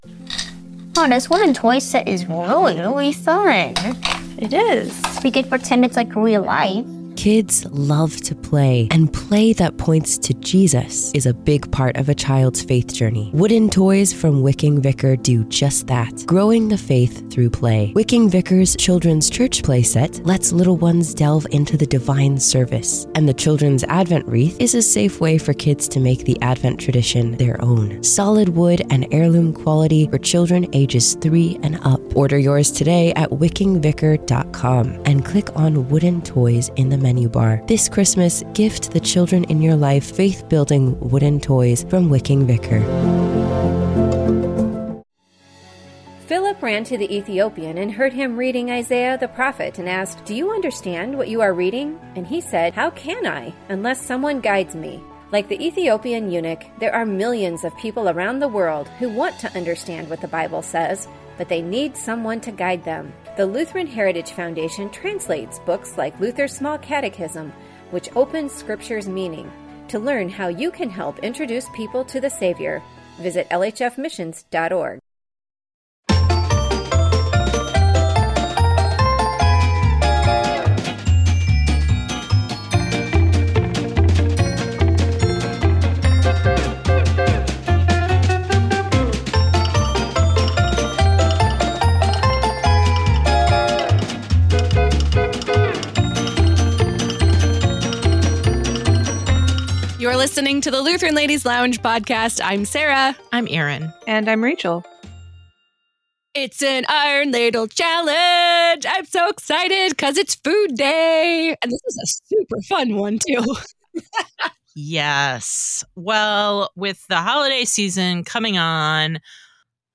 Have you scrolled through your podcasts, searching for one that catches your ear - a place you can escape to with inviting conversations, laughter, and fellowship with your Lutheran sisters?